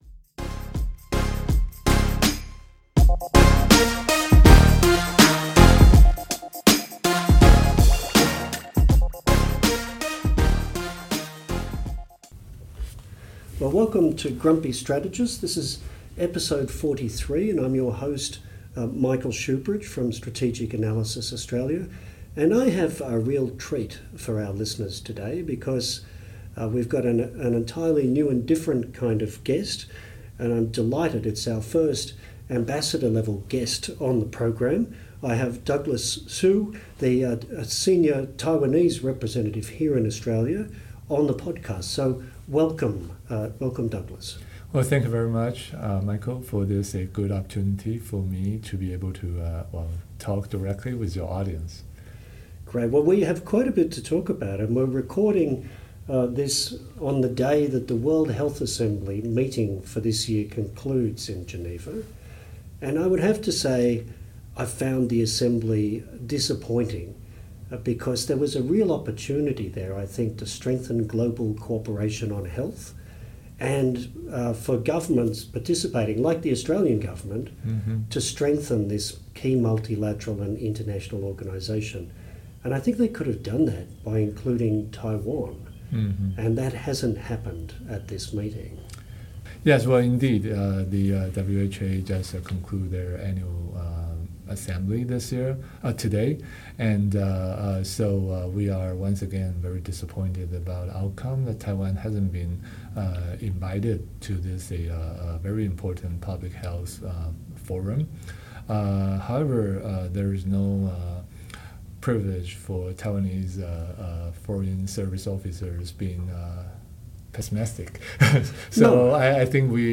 Episode 33 - Grumpy Strategists live at the Australian Defence Magazine 2025 Congress - The Grumpy Strategists - Podcast